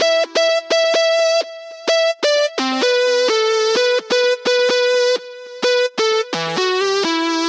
描述：在Buzz中，通过一些旋转和失真效果，对一个风琴合成器的单个音符进行立体声录音。攻击是用压缩器加上去的，音符保持了7秒钟。
标签： 嗡嗡声 扭曲 器官 旋转式 合成器
声道立体声